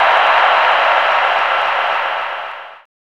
3706L CROWD.wav